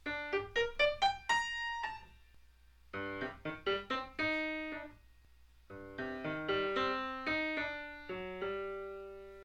Musical demonstration of how Beethoven borrowed a theme from Mozart's 40th Symphony for his own Fifth Symphony. Recorded on an acoustic piano